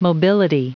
Prononciation du mot mobility en anglais (fichier audio)
Prononciation du mot : mobility